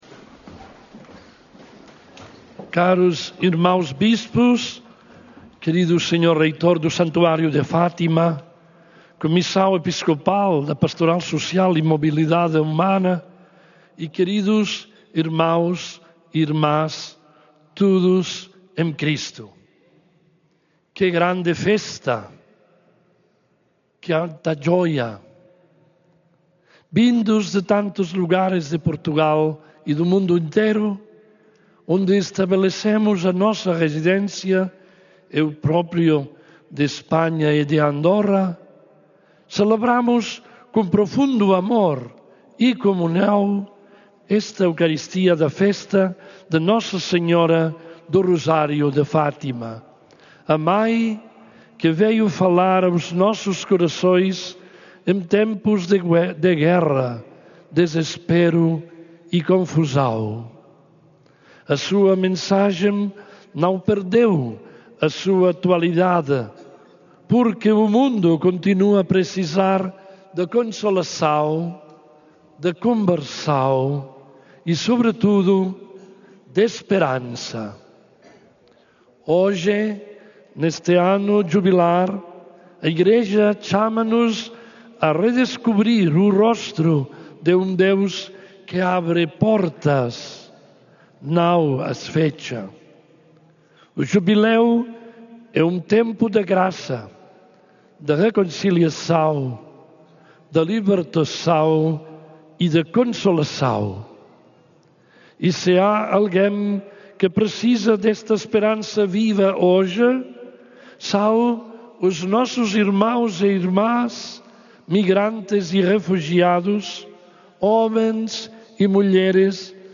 Na homilia da missa Internacional Aniversária de 13 de agosto, arcebispo emérito de Urgell exortou ao acolhimento dos "mensageiros de esperança".
D. Joan-Enric Vives, arcebispo emérito de Urgell, que preside à Peregrinação de 12 e 13 de agosto, em Fátima, apelou, na homilia da missa internacional aniversária desta manhã, ao acolhimento dos migrantes e refugiados, que considerou serem testemunhas privilegiadas da esperança.